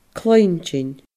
cluinntinn /kLɯ̃ĩNʲdʲɪNʲ/